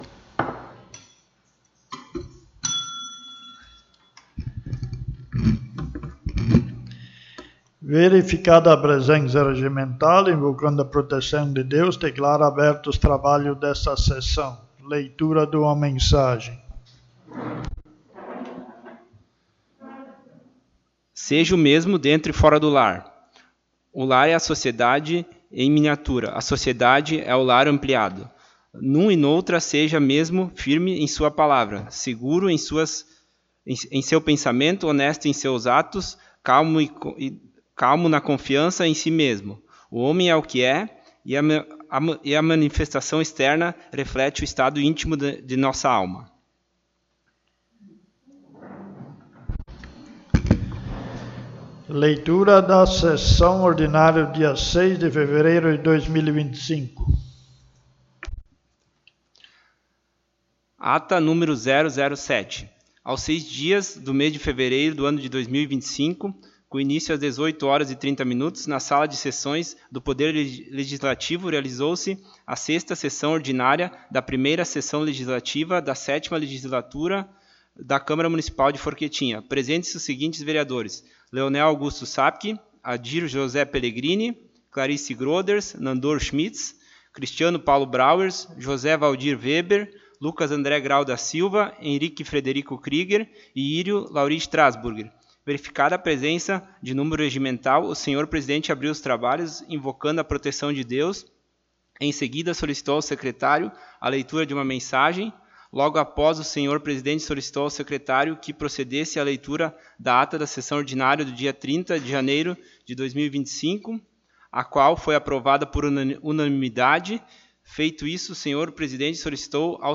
7ª Sessão Ordinária
O espaço da tribuna foi utilizado pela vereadora Clarice Groders.